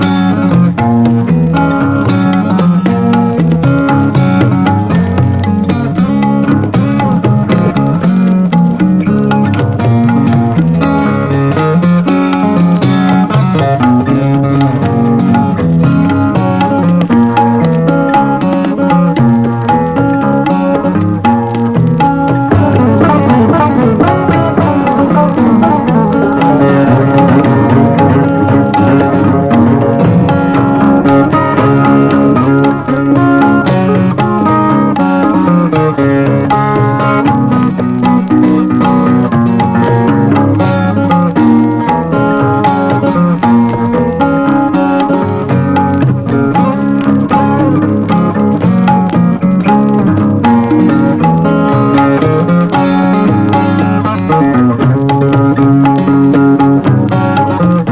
BLUES ROCK / HARD ROCK